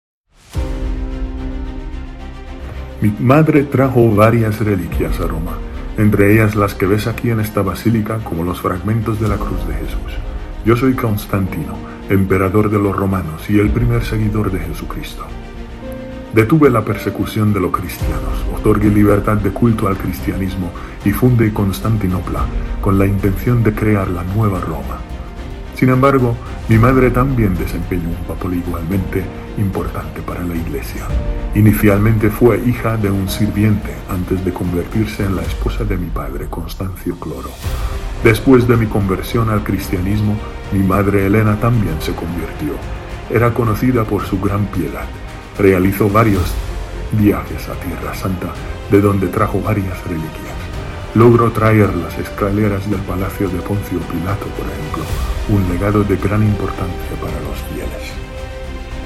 Constantino-with-music.mp3